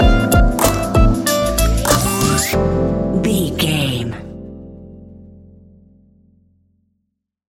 Ionian/Major
Lounge
sparse
new age
chilled electronica
ambient